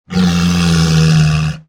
Морж завывает